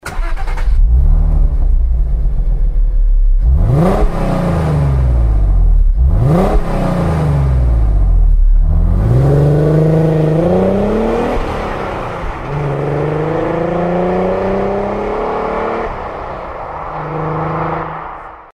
Звуки двигателя